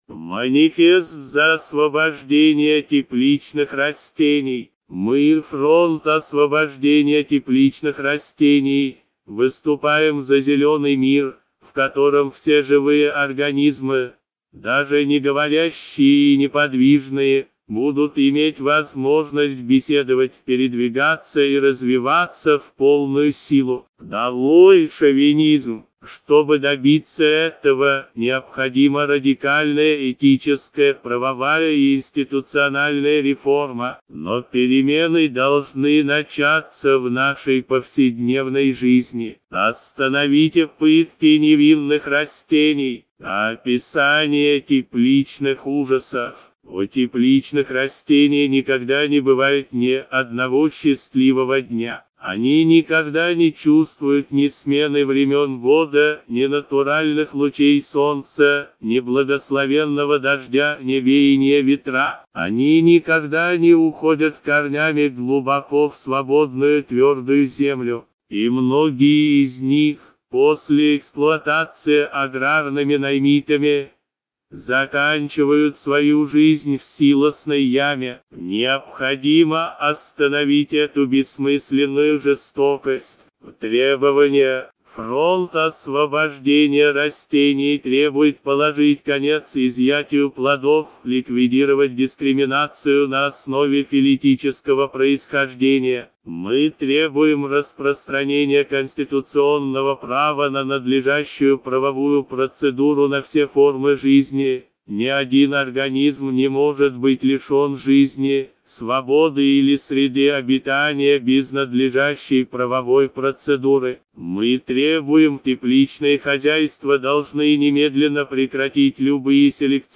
Голос «Левитан», синтез речи нейросетью